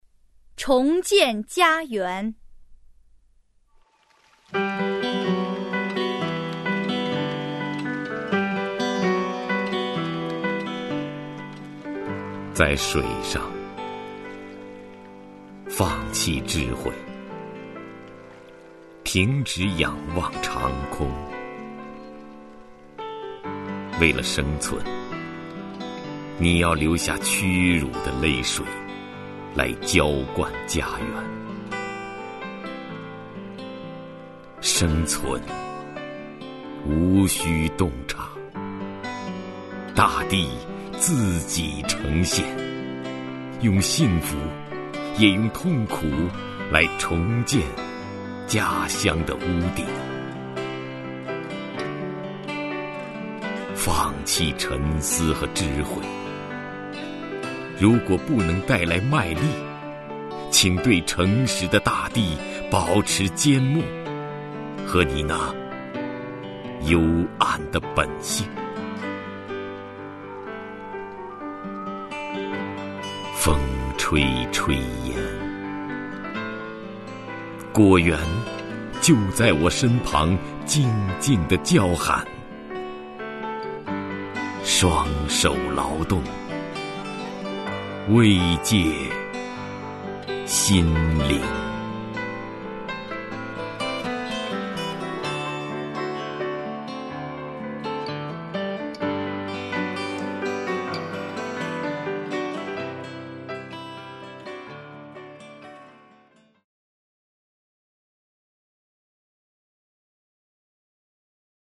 首页 视听 名家朗诵欣赏 徐涛
徐涛朗诵：《重建家园》(海子)